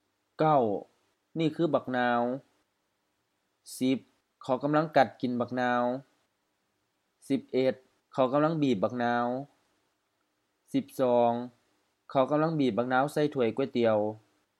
Notes: pronunciation: often with rising tones, especially in isolation